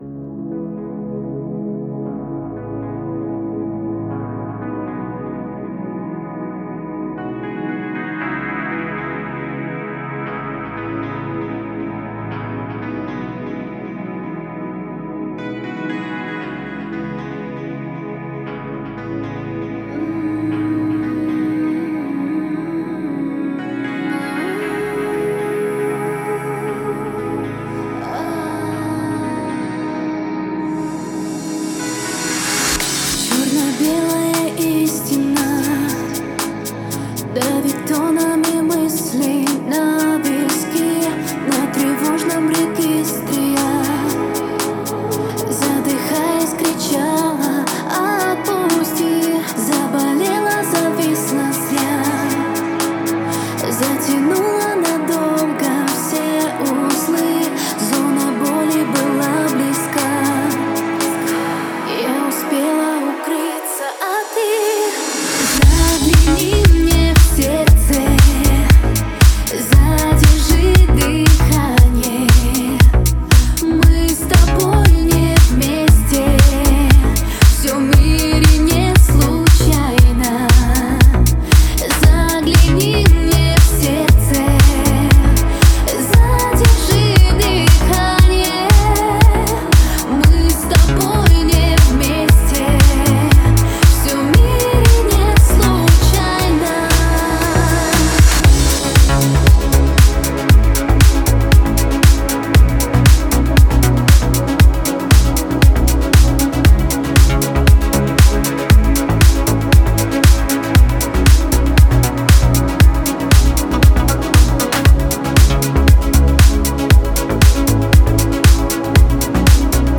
это яркая композиция в жанре электронной музыки